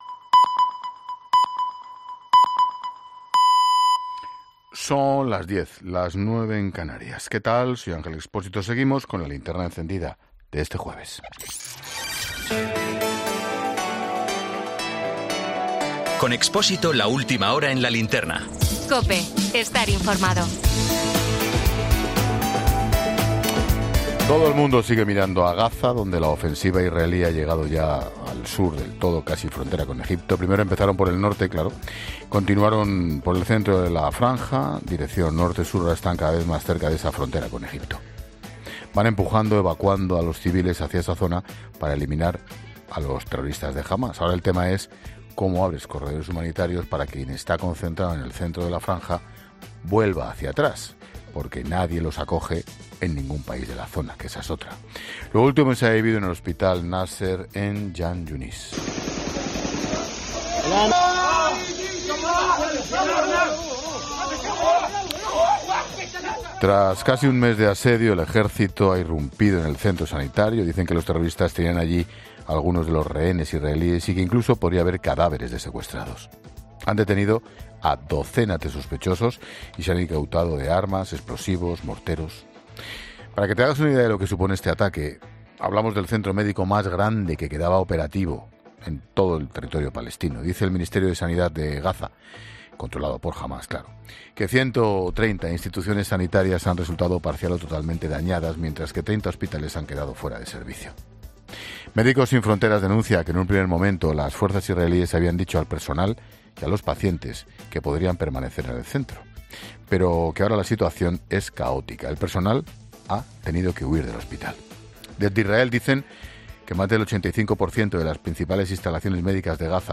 Boletín 22.00 horas del 15 de febrero de 2024 La Linterna